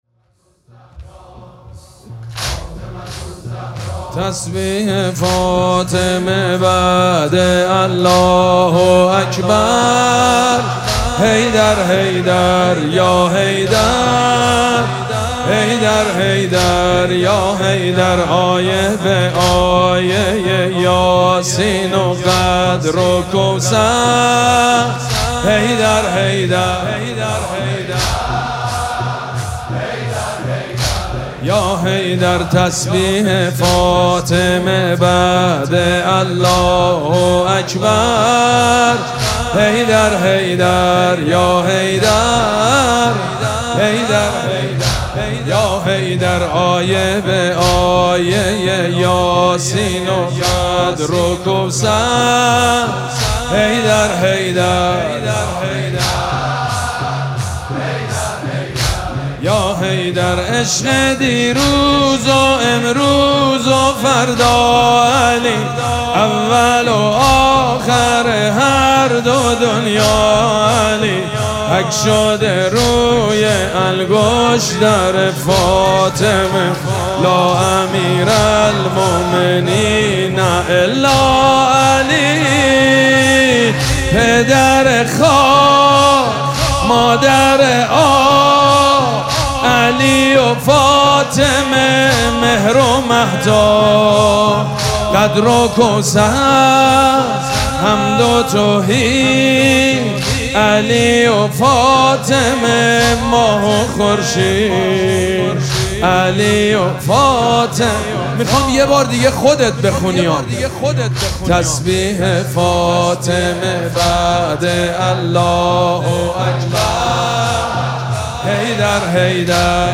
شب پنجم مراسم عزاداری دهه دوم فاطمیه ۱۴۴۶
حسینیه ریحانه الحسین سلام الله علیها
حاج سید مجید بنی فاطمه